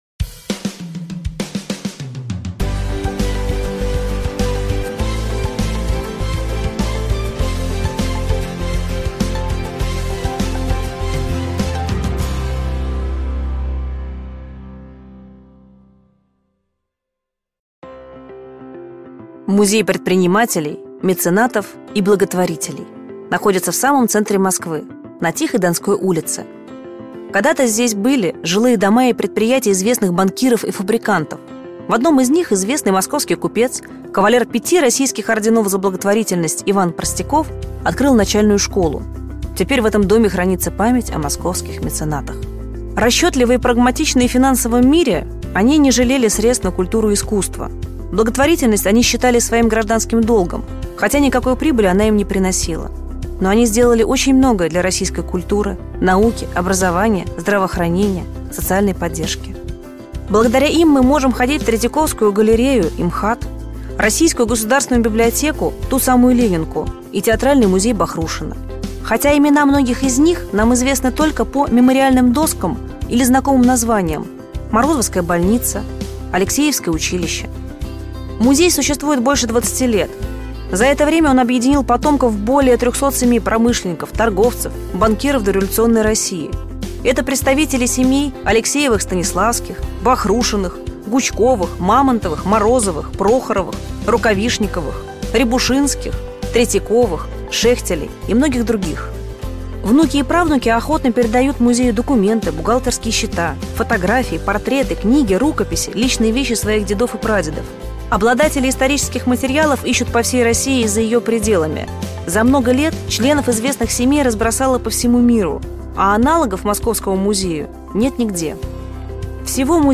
Аудиокнига Бизнес как практическая психология | Библиотека аудиокниг